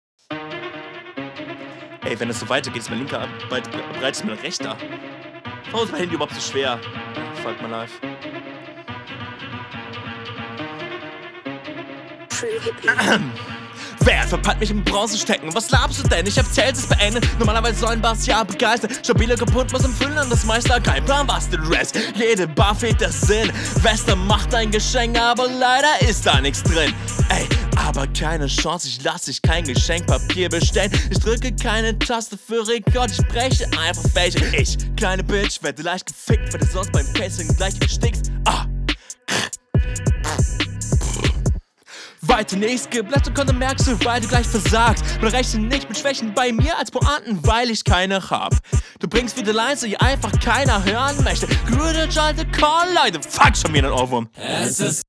Du flowst zwischendrin sehr nuschelig und auch einfach nicht richtig auf dem Takt.